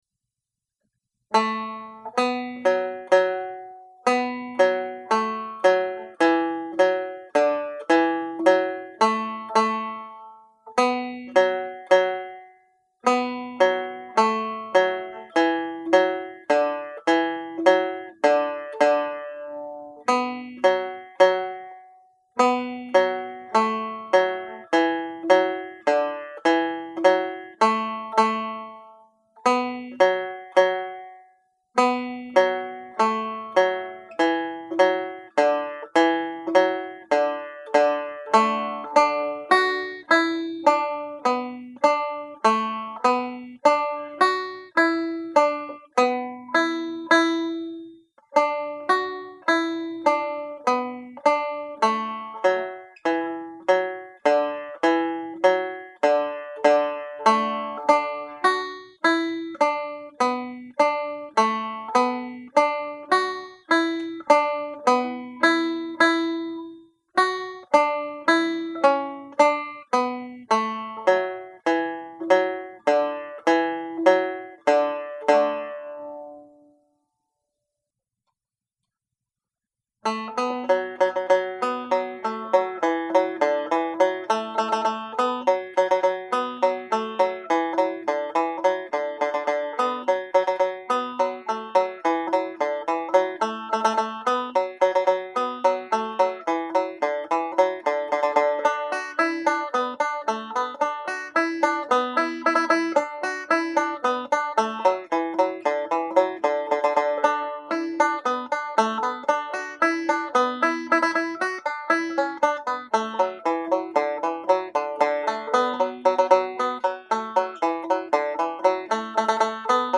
Reel (D Major)